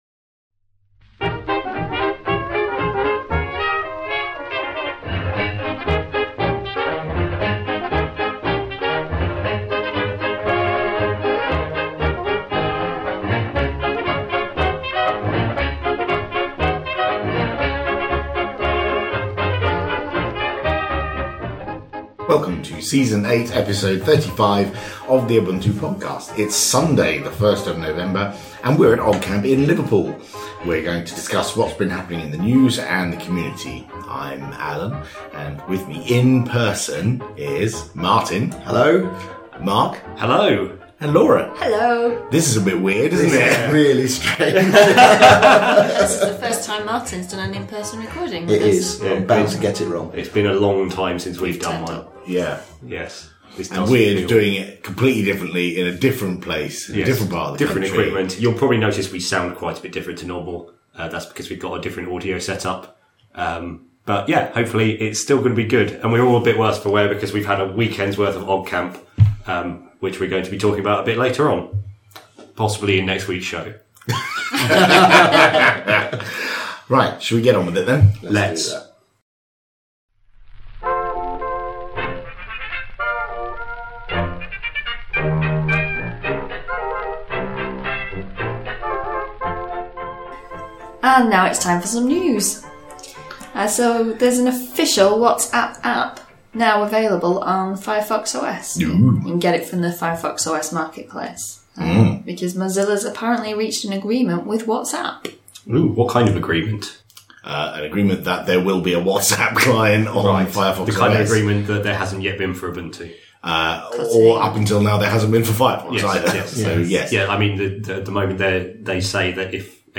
recording together, in-person, and face-to-face across a dining-room table in Liverpool!